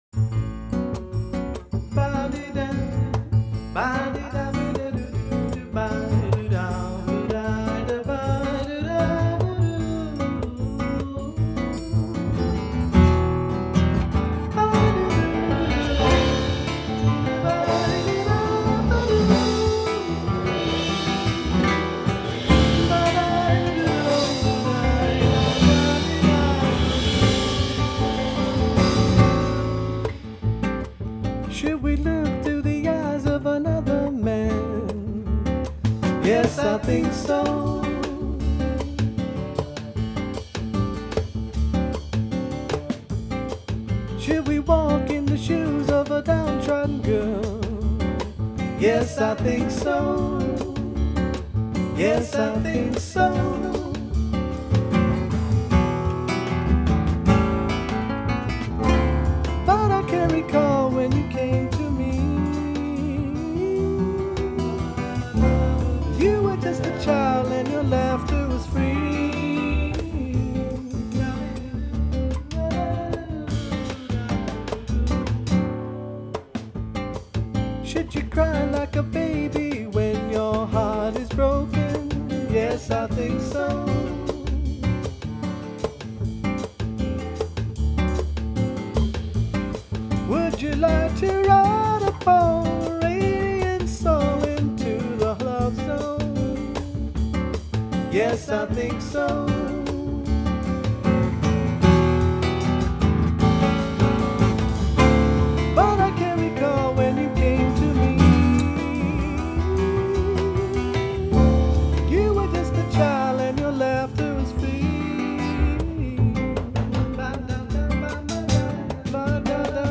guitar, traps, voices